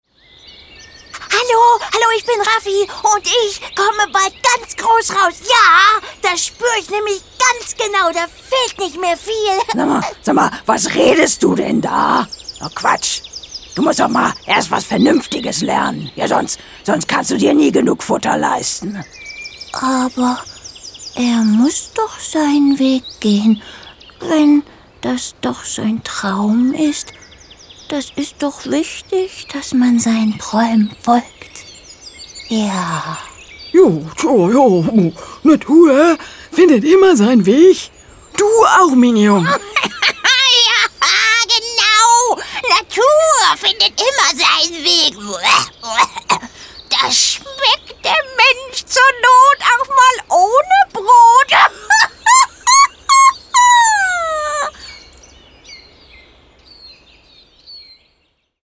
markant, sehr variabel, dunkel, sonor, souverän
Mittel plus (35-65)
Norddeutsch
Hörspiel - Trickstimmen-Dialog
Audio Drama (Hörspiel), Children's Voice (Kinderstimme), Comedy, Game, Scene, Trick